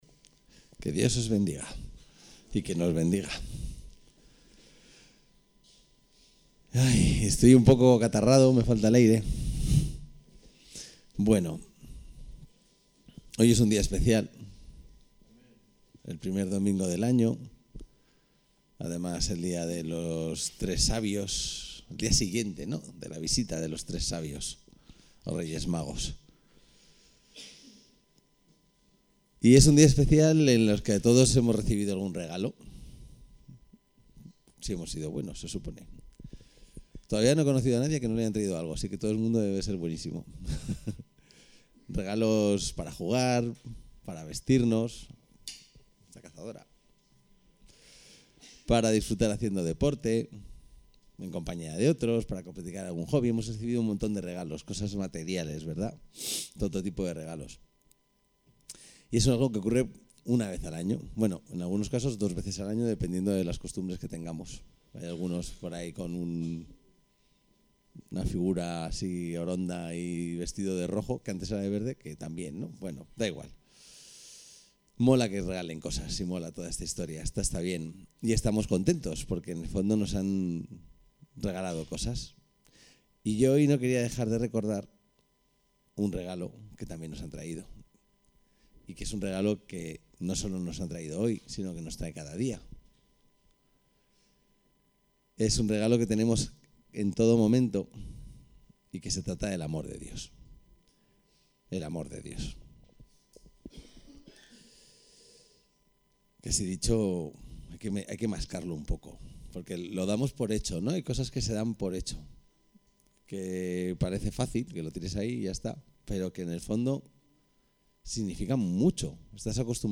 El texto de la predicación se puede descargar aquí -> El perdon el mayor regalo de Dios